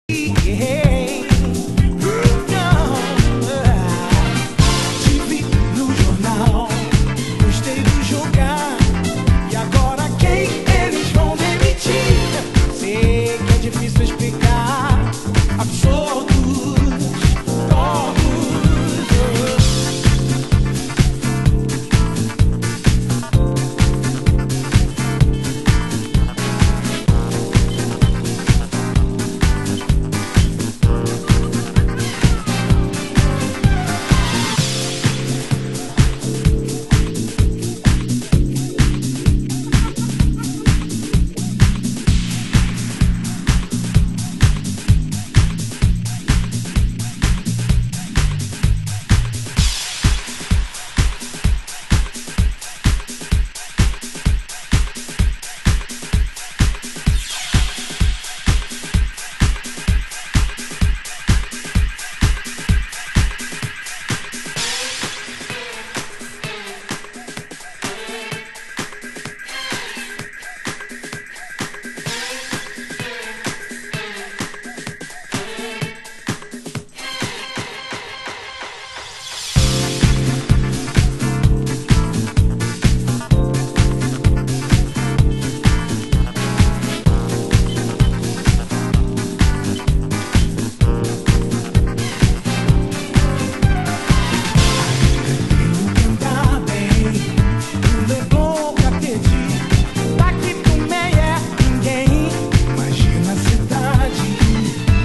盤質：盤面良好ですが、少しチリパチノイズ有